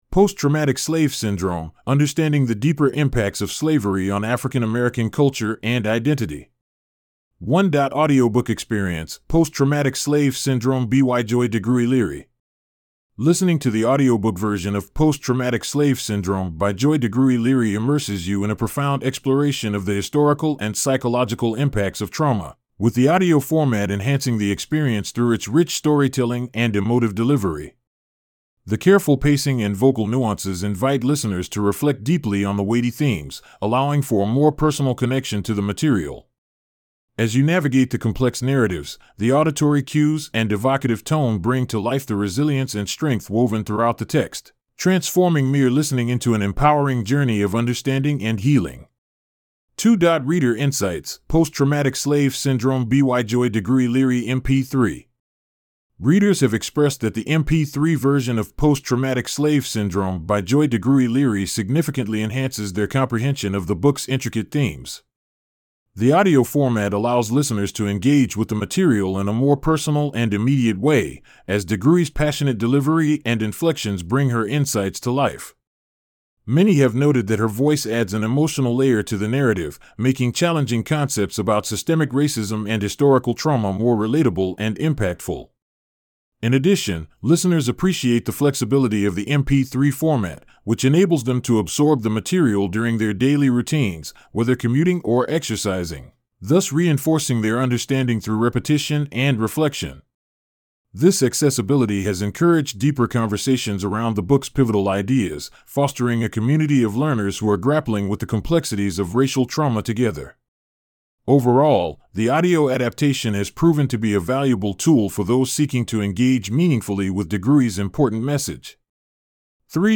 1.Audiobook Experience:Post Traumatic Slave Syndrome BYJoy DeGruy Leary